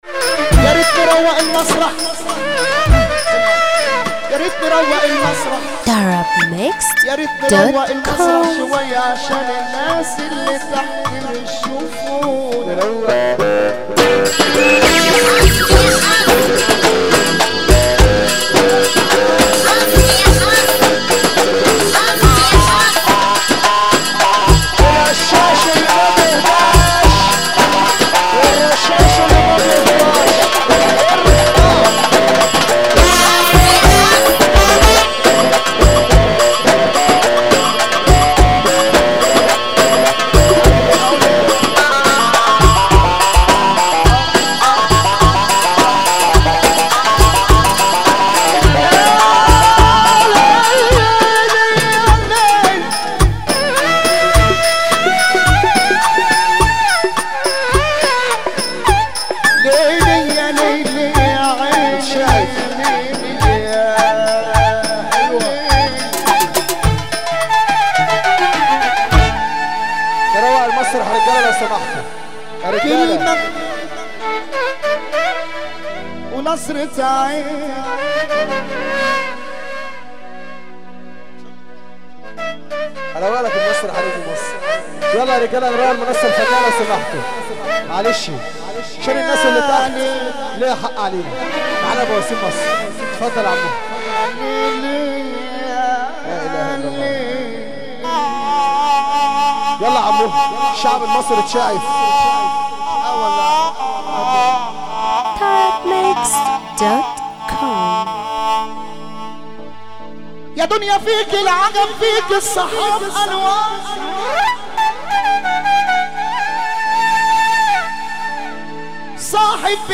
موال
حزين موت